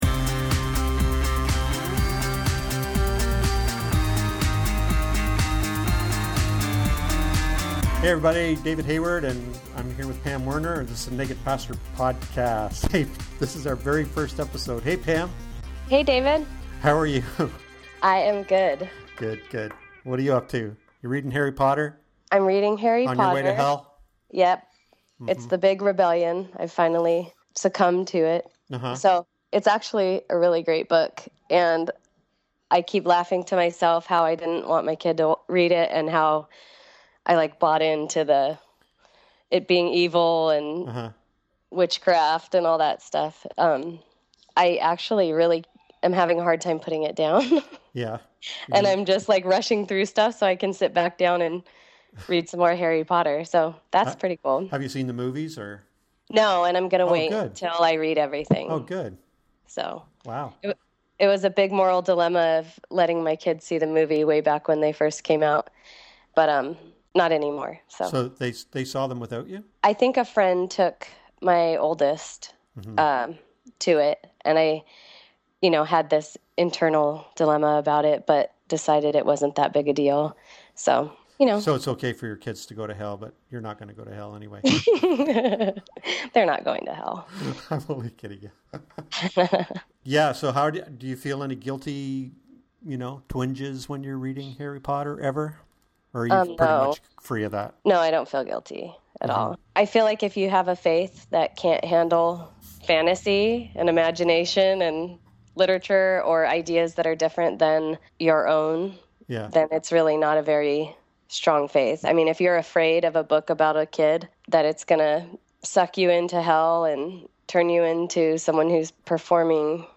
We’re going to just chat about stuff that’s been going on in our lives and online.